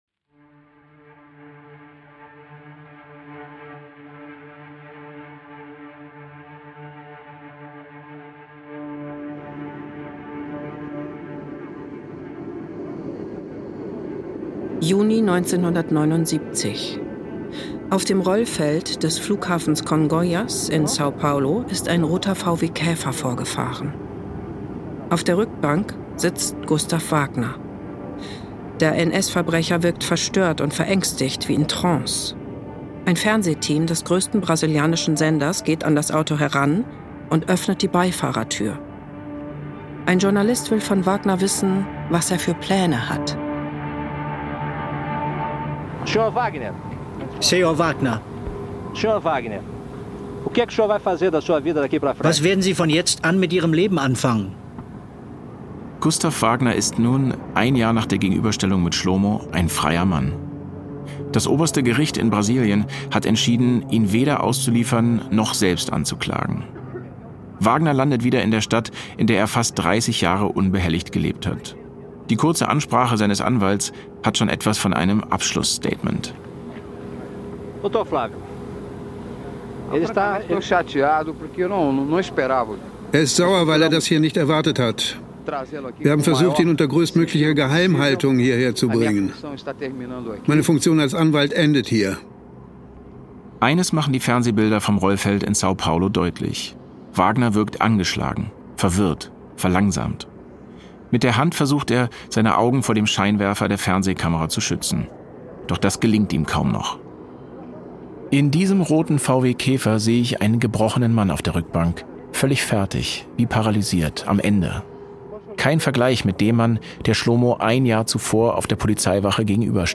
Ein alter Kriminalpolizist, der als erster die Leiche Wagners in Augenschein nahm, schildert seine Beobachtungen.
Ein Landarbeiter sah Wagner als letzter noch lebend, berichtet hier erstmals von dessen letzten Minuten.